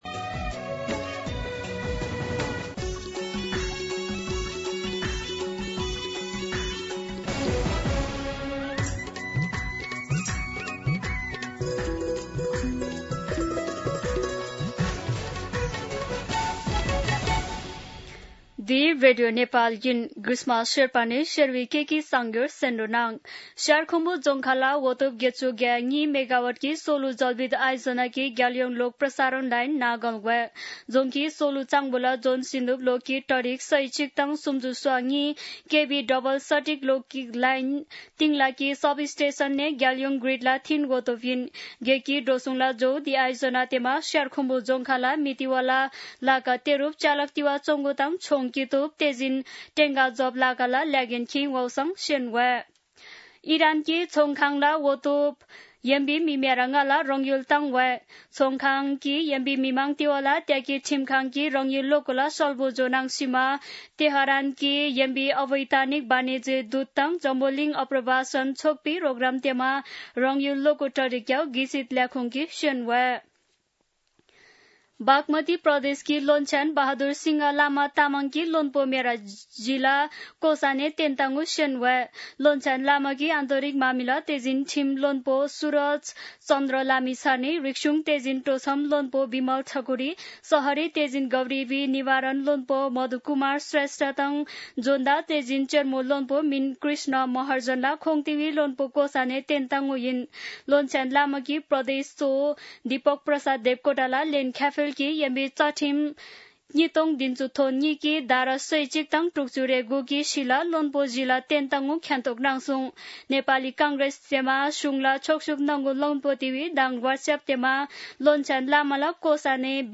शेर्पा भाषाको समाचार : १० साउन , २०८२
Sherpa-News-1.mp3